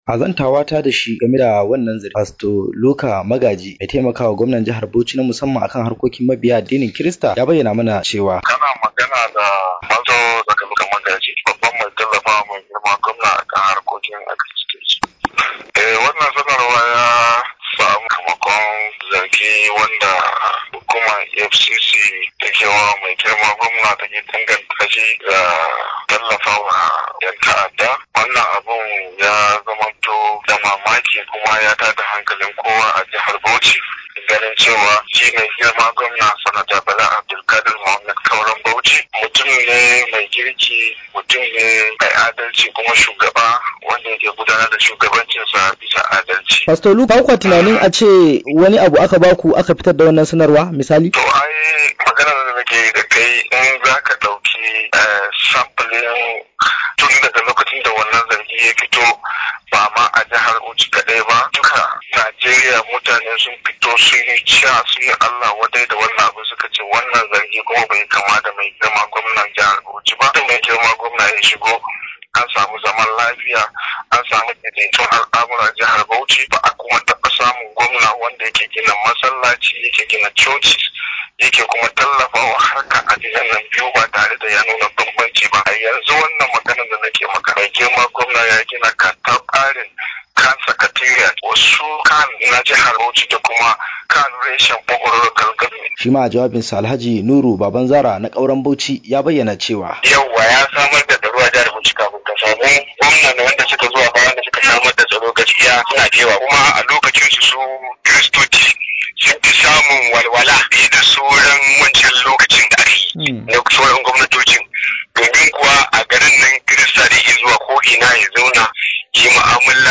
daga Gombe